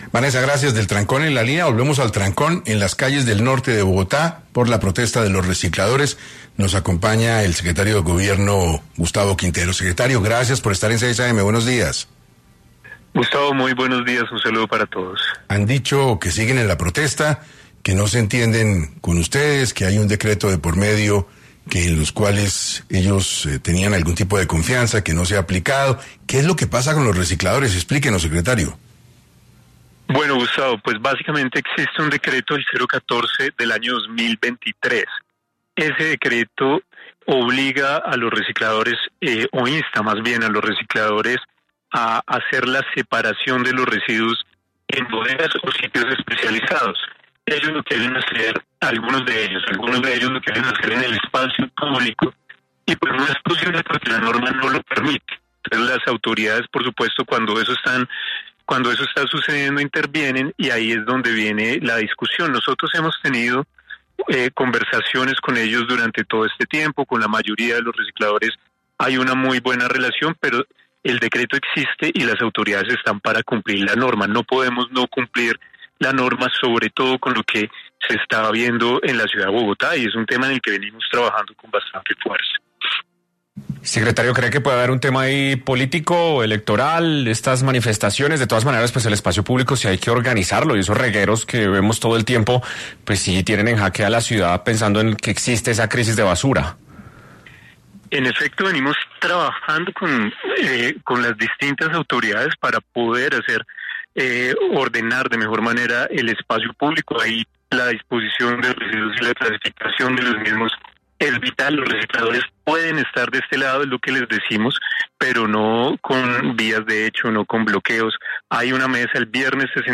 El Secretario de Gobierno de Bogotá, Gustavo Quintero, estuvo hablando sobre la situación de basuras que se está viviendo en Bogotá en 6 AM de Caracol Radio